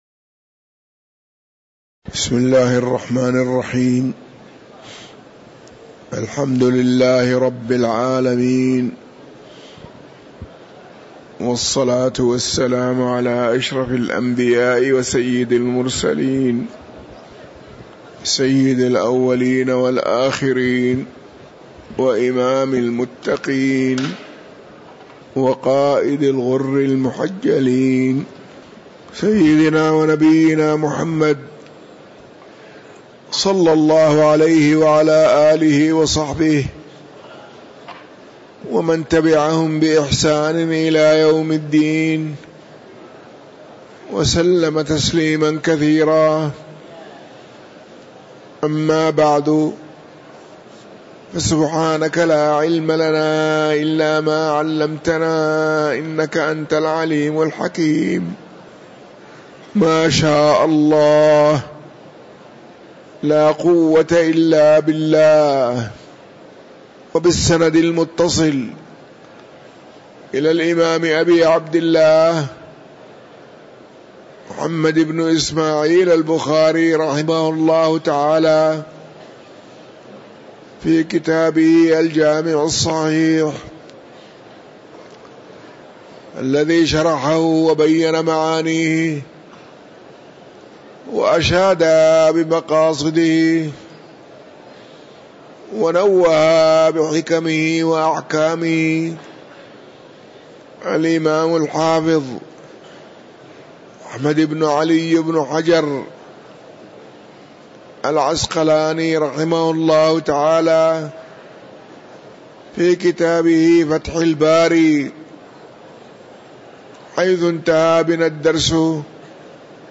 تاريخ النشر ٧ رمضان ١٤٤٥ هـ المكان: المسجد النبوي الشيخ